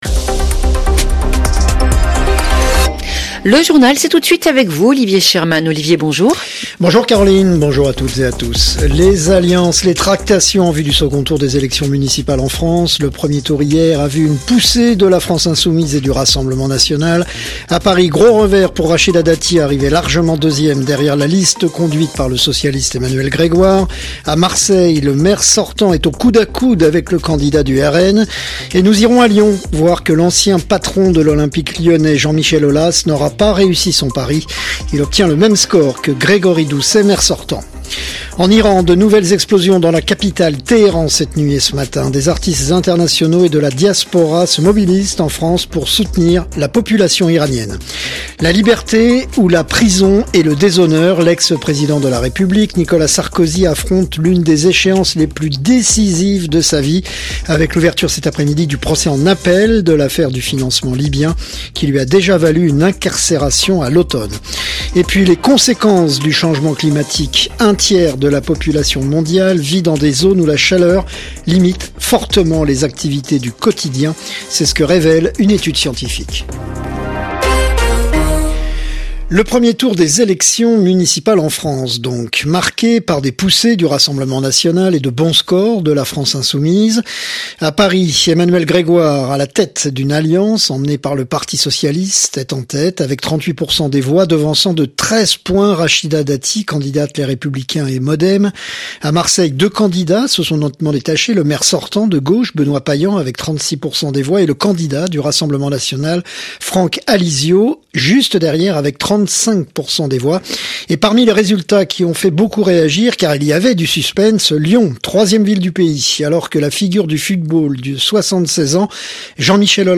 Flash infos 16/03/2026